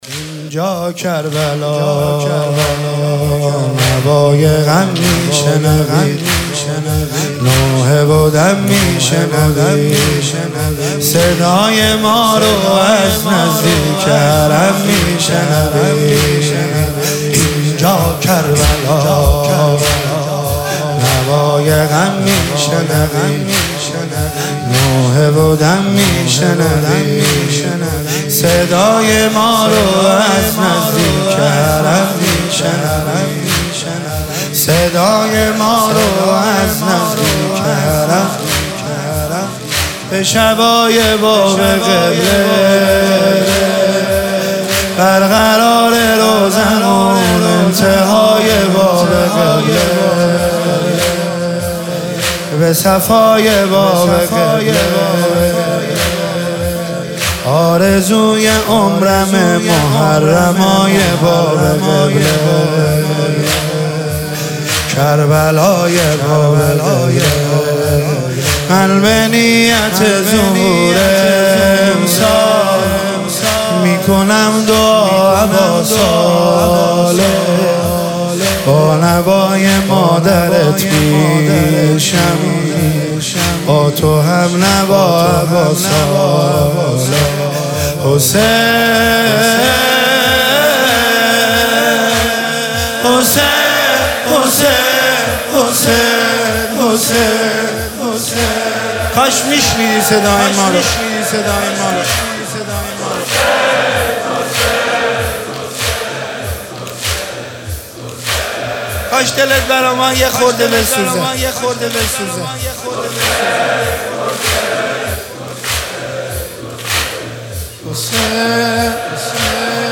شب سوم محرم 1404